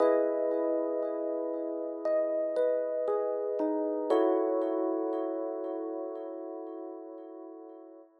04 ElPiano PT1.wav